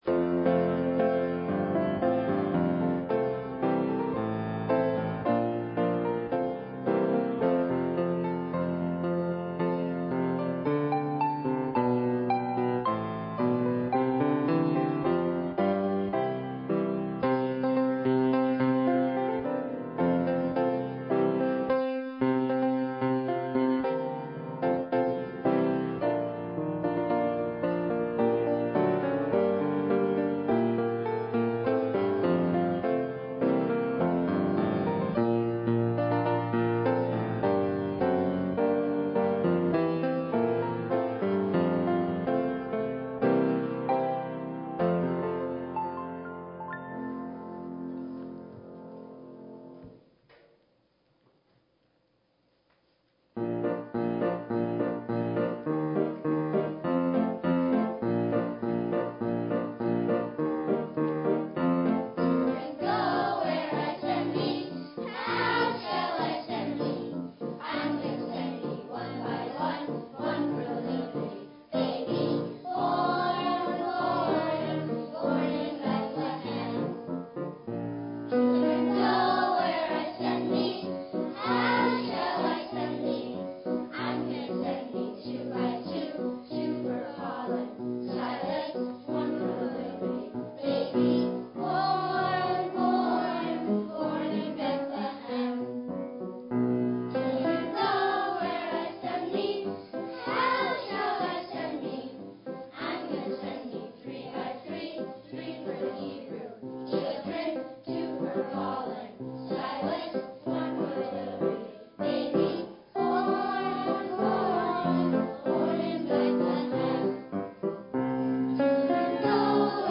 Love in the Church Sermon #5 - Beneficence of Love in the Church - Charity - Robinson Baptist